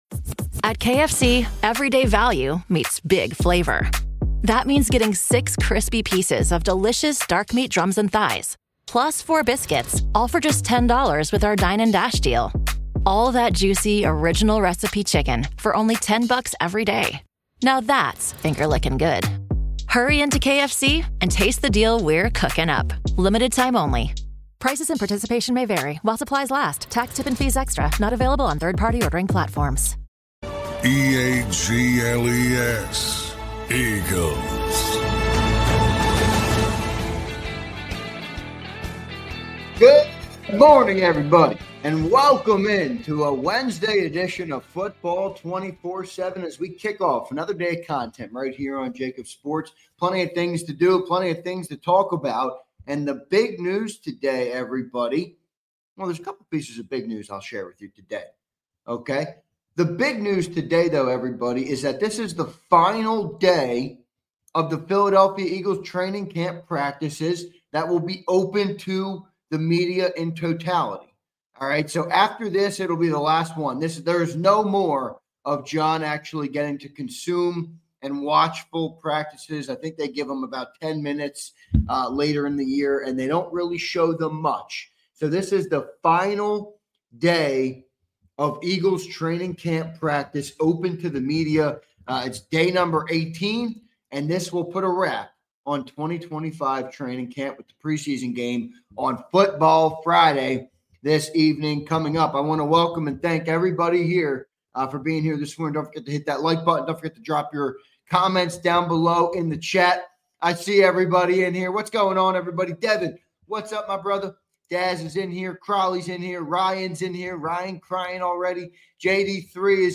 Philadelphia Eagles: Football 24/7 is live with our consistent Eagles coverage whenever news breaks or the Eagles are getting to work.